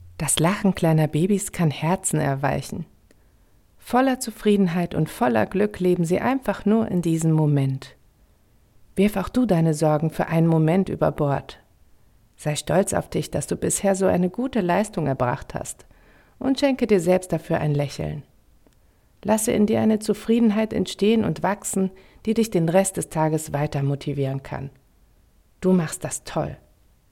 Sprechproben
emotional/ sanft – App-Inhalt
Sprechprobe-emotional-sanft-.mp3